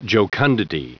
Prononciation du mot jocundity en anglais (fichier audio)
Prononciation du mot : jocundity